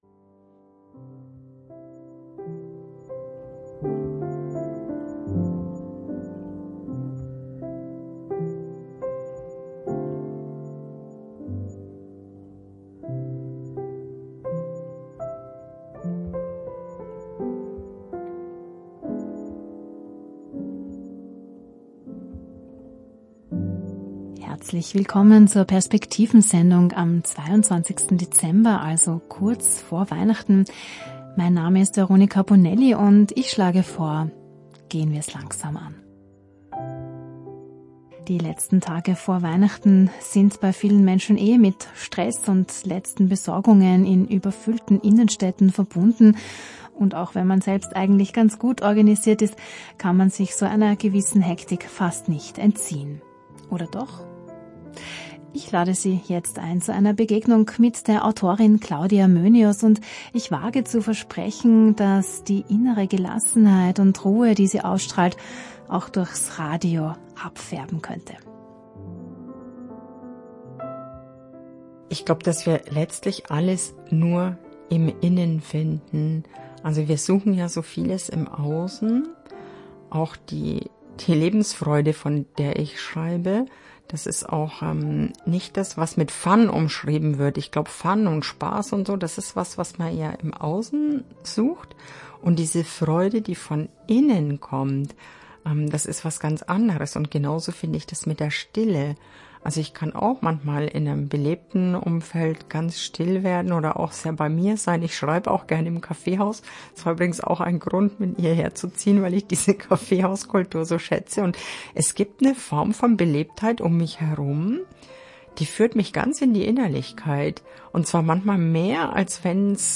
Radiointerview radio klassik Stephansdom Gestaltung der Sendung